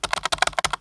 Keyboard1.wav